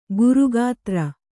♪ guru gātra